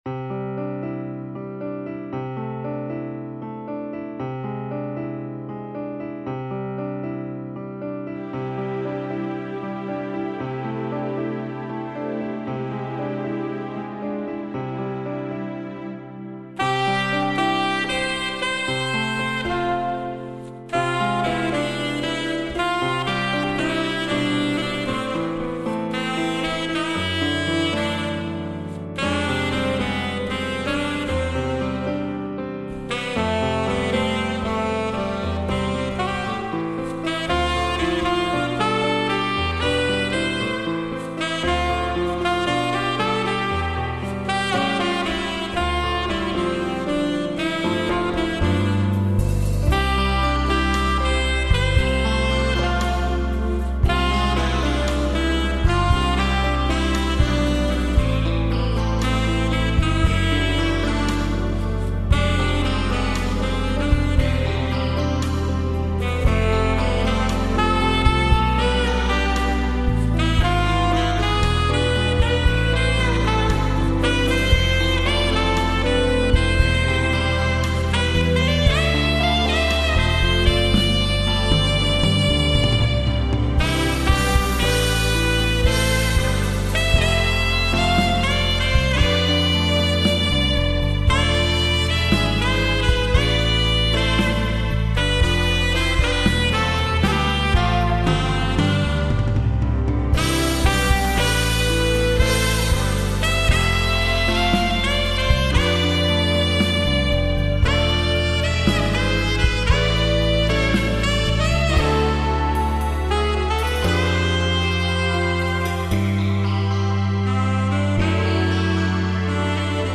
셀마 리퍼런스 54 알토 피스